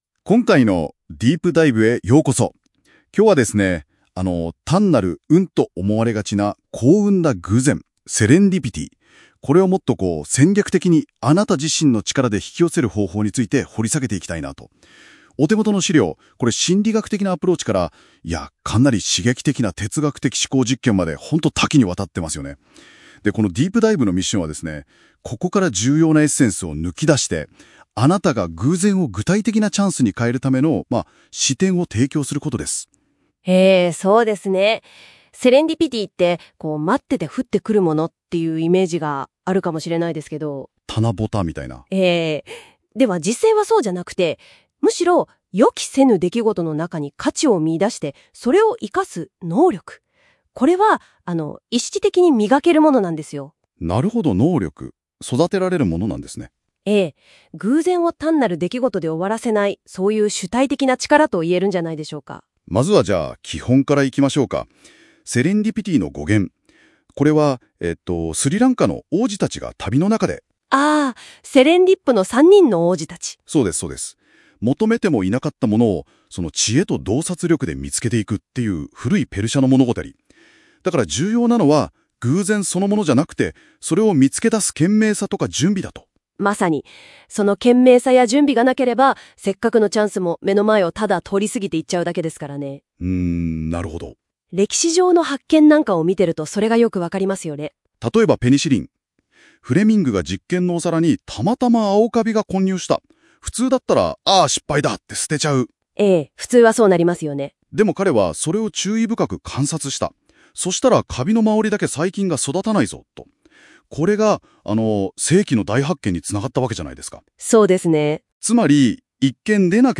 【音声解説】シミュレーション仮説で紐解く！「運」を戦略的に引き寄せる幸運の法則と世界の裏技